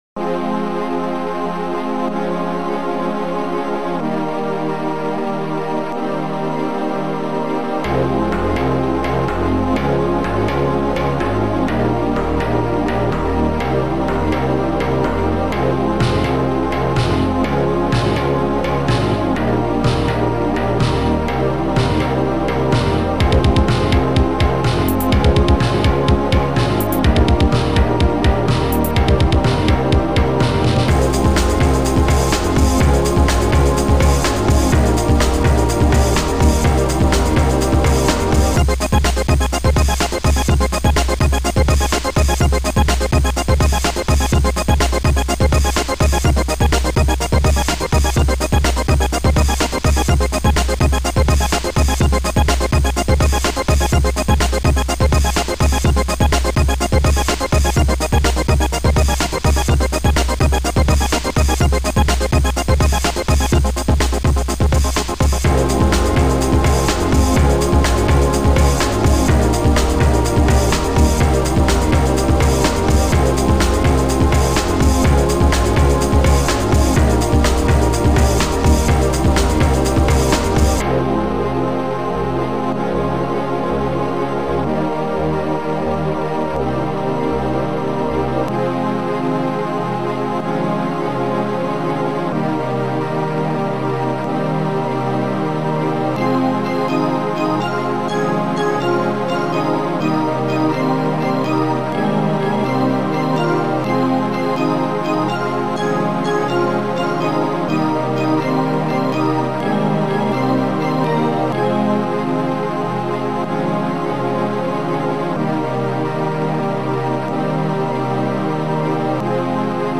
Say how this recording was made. auf einem Amiga 500 & Amiga 4000.